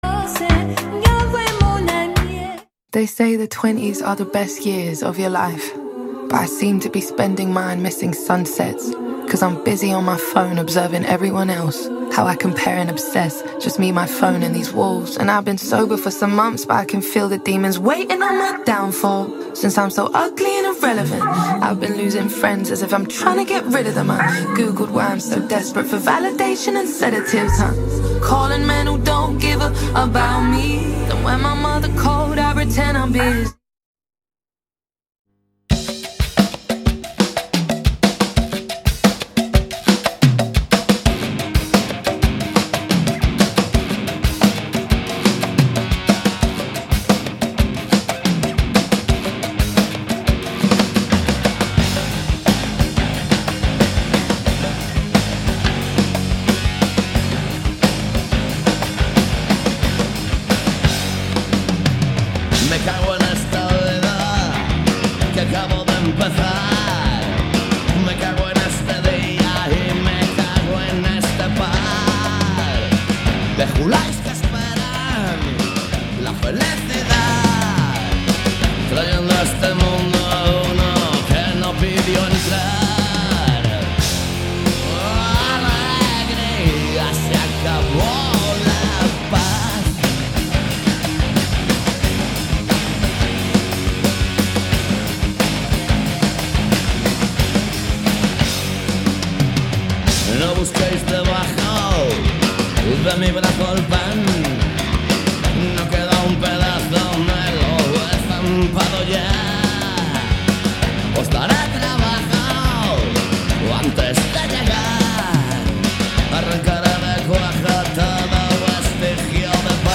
Todo iso misturado con boa música e un pouco de humor se o tema o permite. Cada martes ás 18 horas en directo.
Alegría comezou a emitirse en outubro de 2003 e dende entón non falla nas ondas de CUAC FM.